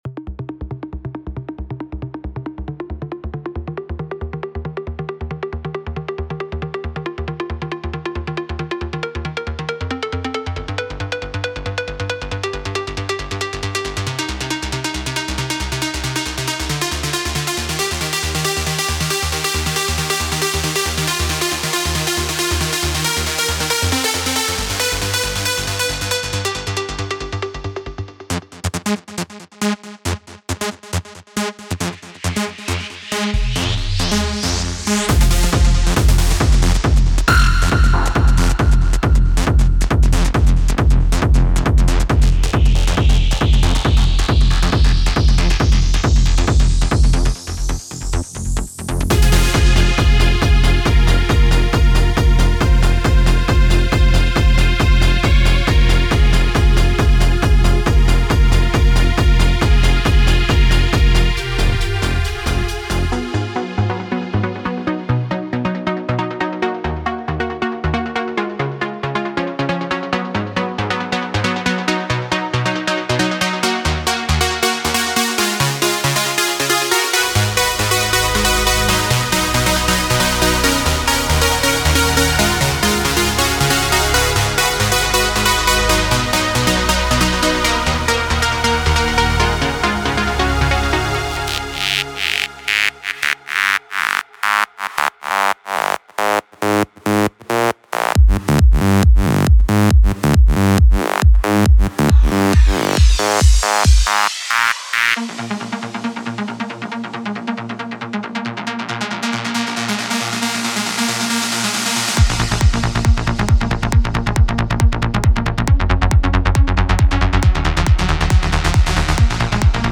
trance sounds for xfer serum synthesizer inspired by iconic trance tracks
Basses, Leads, Pads and plucks, even digital vocal-like sounds, with just a handful of drum and FX sounds for good measure.
Adding stereo waveforms and noise goes a long way, along with waveforms reminiscent of classic digital wave playback (PCM) synths.
MP3 DEMO
AZS Serum Classic Trance Demo.mp3